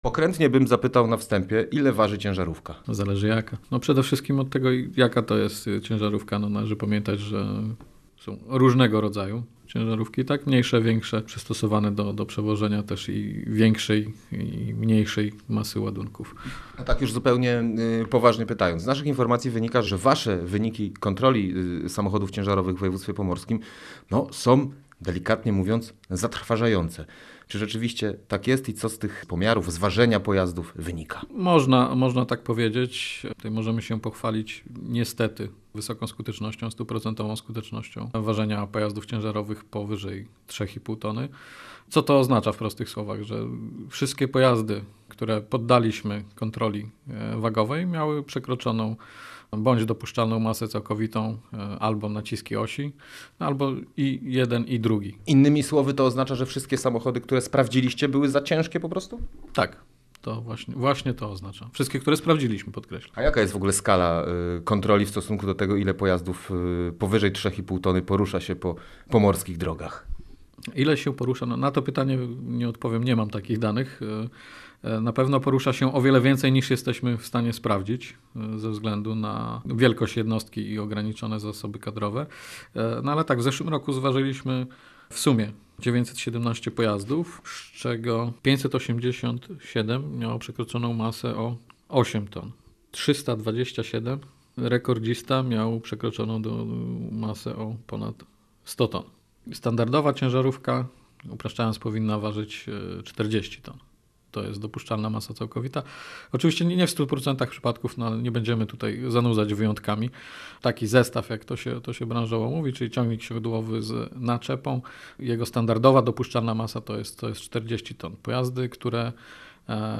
Gość Radia Gdańsk